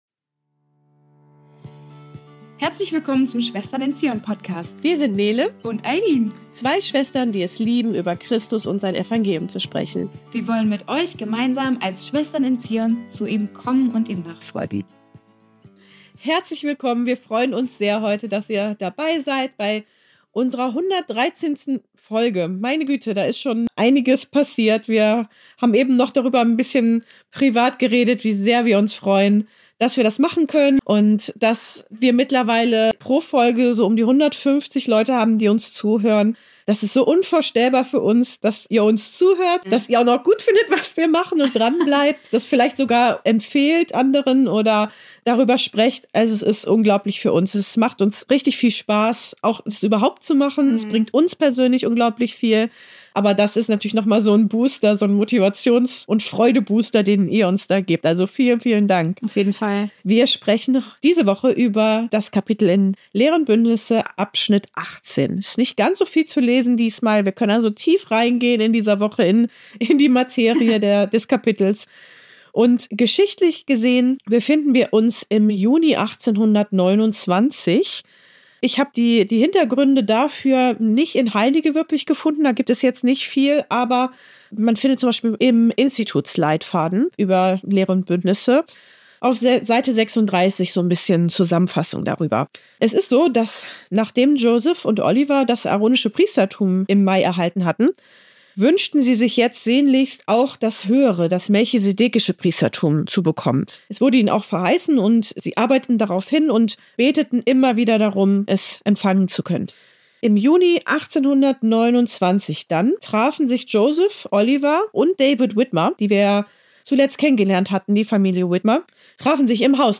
Über diese Fragen und viele mehr sprechen wir in dieser Folge! PS: In der Folge haben wir ein paar technische Aussetzer.